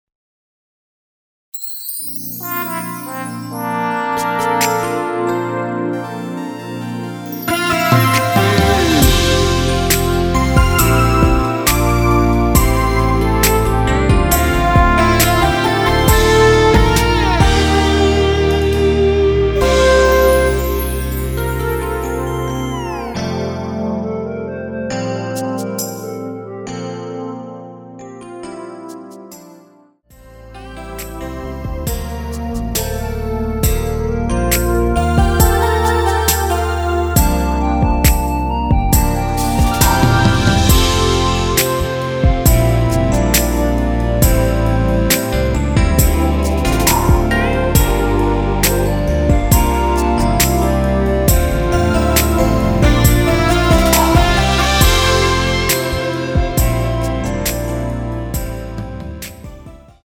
원키에서(-2)내린 (짧은편곡) 멜로디 포함된 MR입니다.
앞부분30초, 뒷부분30초씩 편집해서 올려 드리고 있습니다.
중간에 음이 끈어지고 다시 나오는 이유는